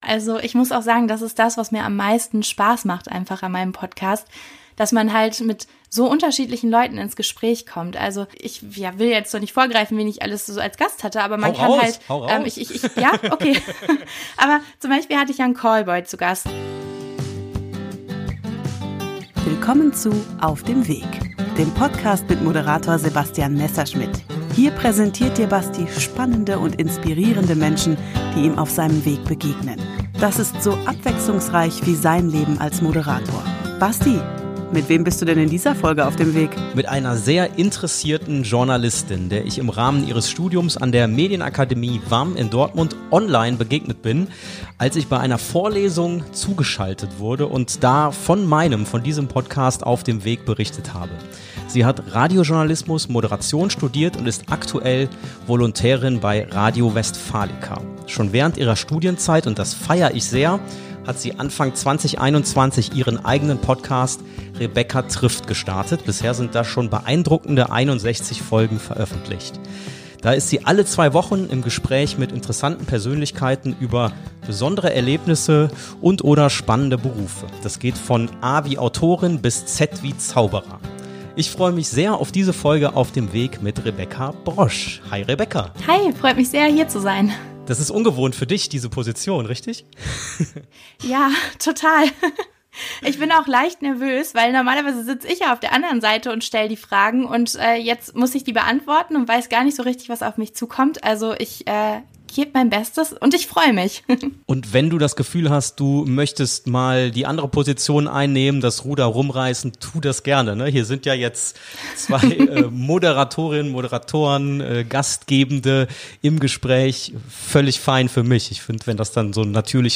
Verbunden per Zoom